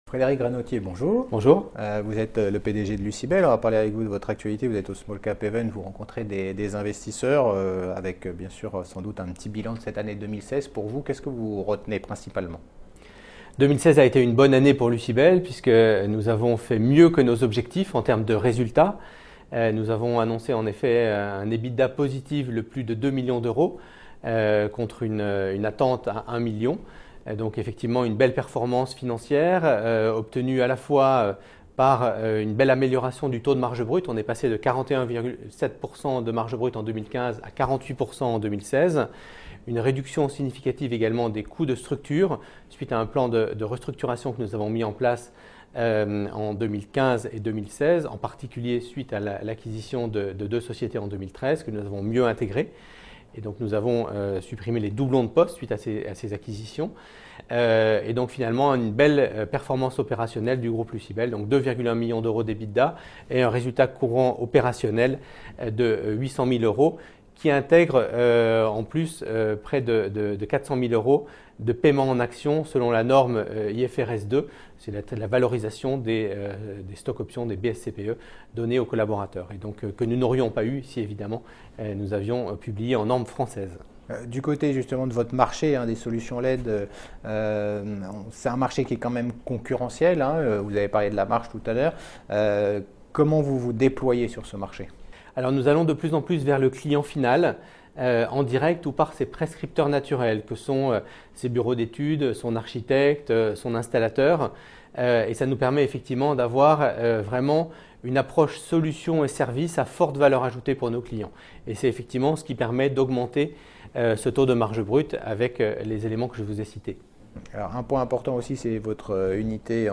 La Web Tv partenaire du SmallCap Event organisé par CF&B Communication.